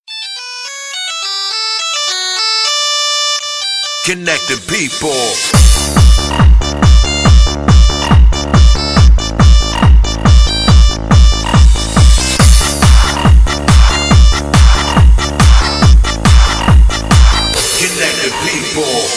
В стиле Техно